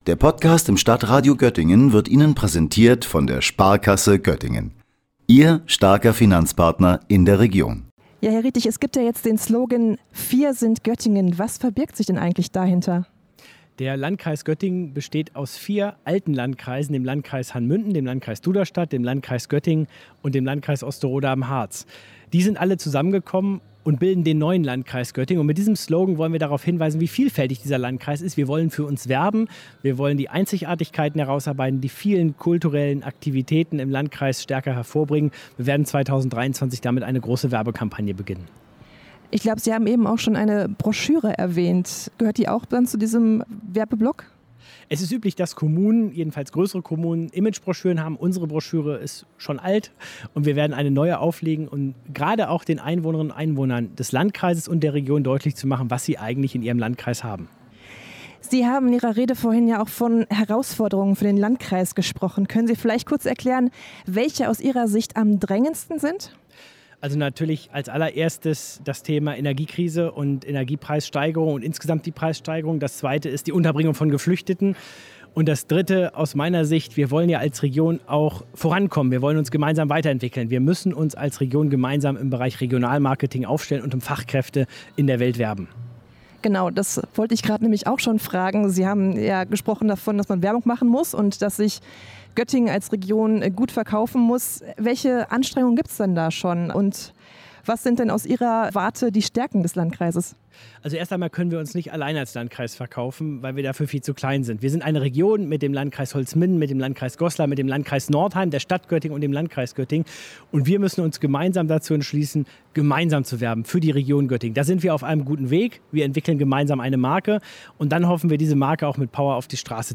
Mehrere Hundert Gäste aus Politik und Gesellschaft sind am vergangenen Mittwoch in der Sheddachhalle im Sartorius Quartier zum Jahresempfang des Landkreises Göttingen zusammengekommen.